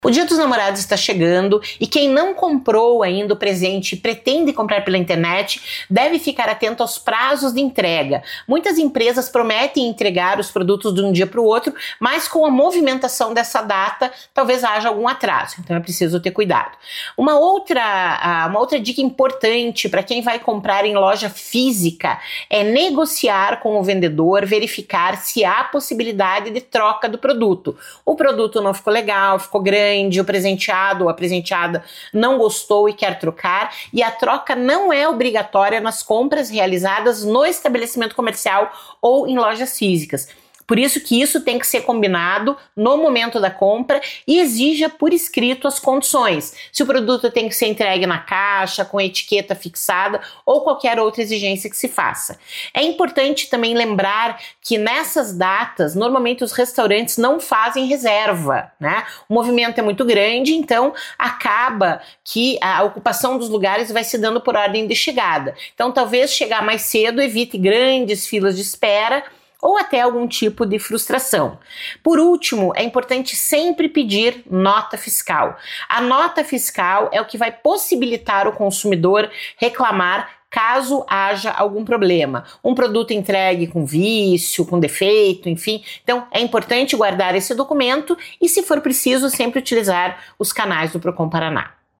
Sonora da coordenadora do Procon-PR, Claudia Silvano, sobre os alertas para compras no Dia dos Namorados